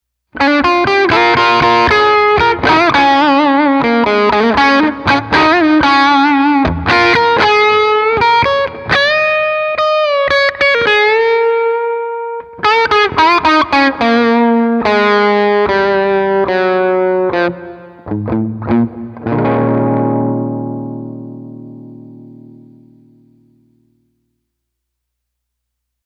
Tutte le clip audio sono state registrate con testata a valvole artigianale da 15W ispirata al Cornell Romany e cassa 1×12 equipaggiata con altoparlante Celestion A-Type impostato su un suono estremamente clean.
Chitarra: Gibson Les Paul (Pickup al ponte)
Turbo: OFF
Engine: 4/10